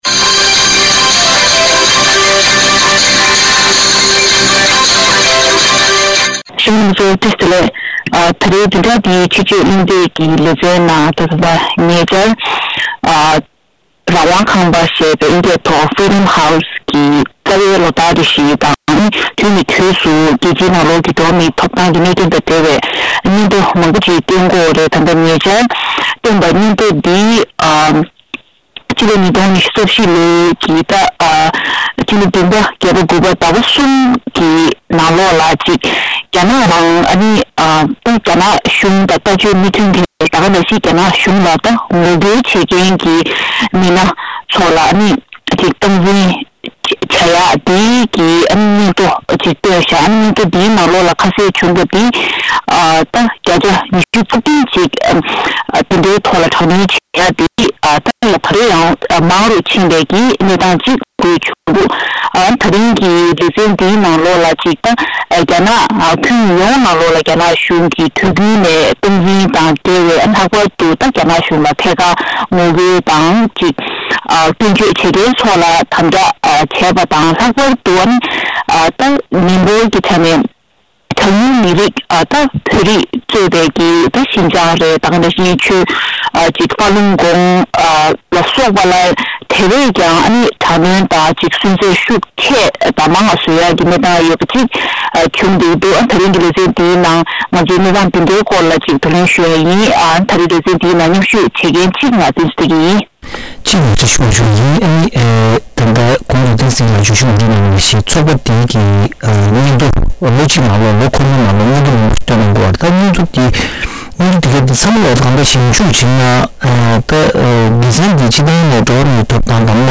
བདུན་རེའི་དཔྱད་བརྗོད་གླེང་སྟེགས་ཀྱི་ལས་རིམ་ནང་།